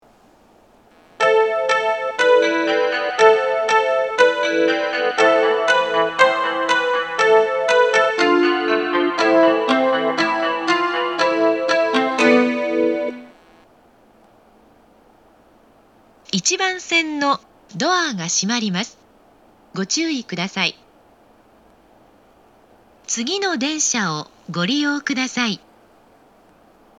この駅は発車放送が「○番線の、ドアが閉まります、ご注意ください。　次の電車を、ご利用ください」となっています。
発車メロディー
フルコーラスです。